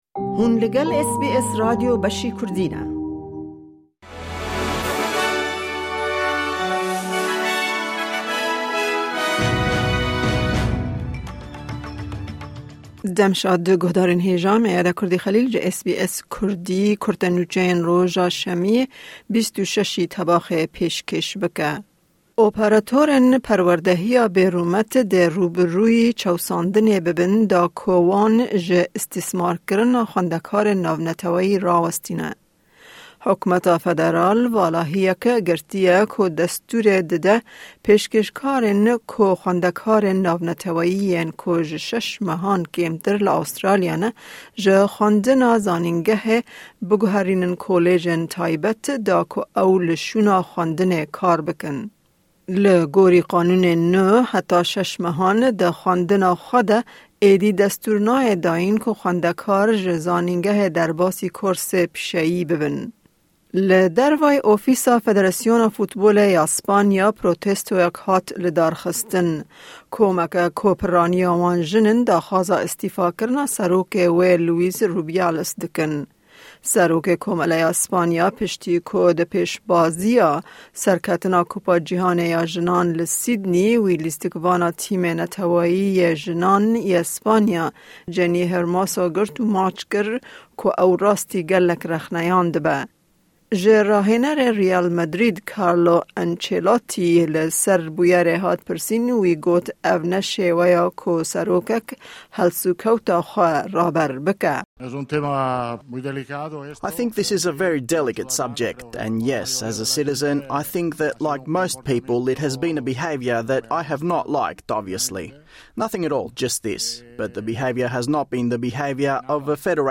Kurte Nûçeyên roja Şemiyê 26î Tebaxê